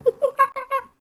Monkey Imitation 2
animal animals ape apes chimp chimpanzee chimpanzees chimps sound effect free sound royalty free Animals